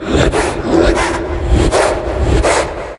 0335ec69c6 Divergent / mods / Soundscape Overhaul / gamedata / sounds / monsters / psysucker / breath_1.ogg 22 KiB (Stored with Git LFS) Raw History
breath_1.ogg